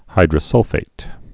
(hīdrə-sŭlfāt)